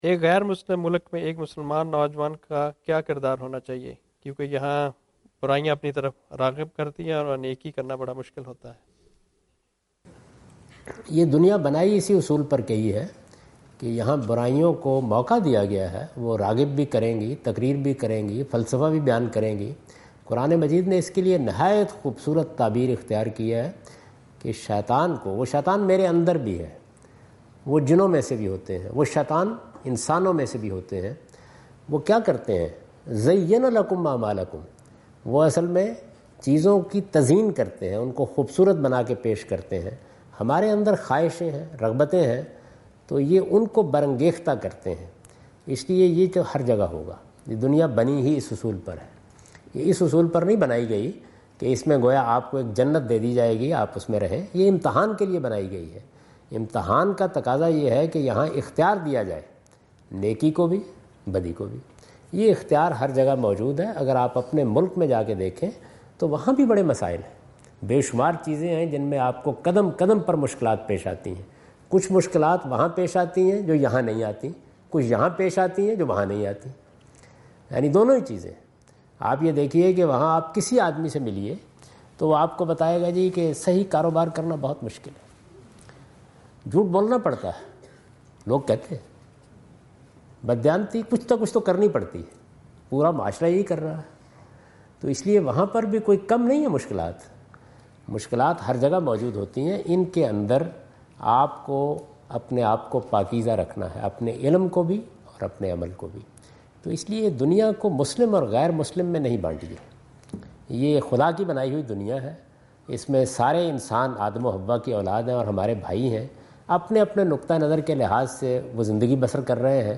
Category: English Subtitled / Questions_Answers /
Javed Ahmad Ghamidi answer the question about "how to refrain from sinful activities?" in Macquarie Theatre, Macquarie University, Sydney Australia on 04th October 2015.